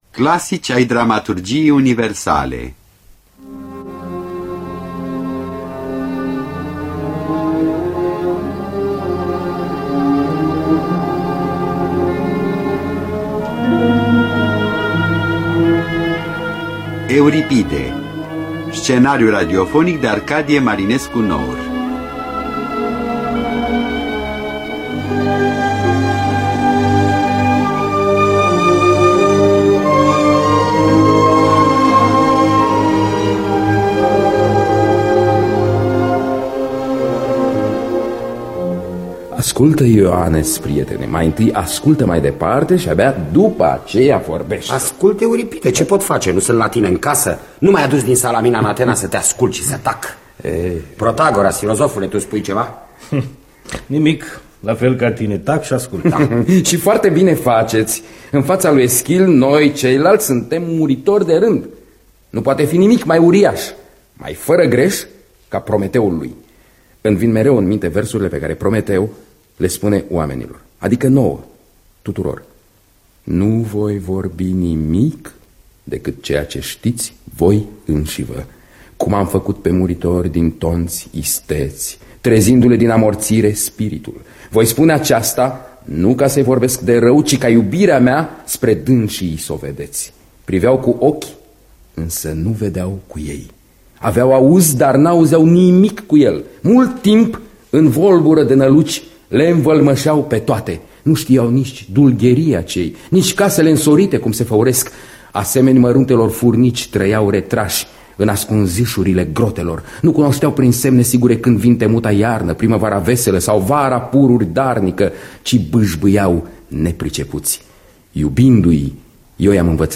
Biografii, memorii: Euripide. Scenariu radiofonic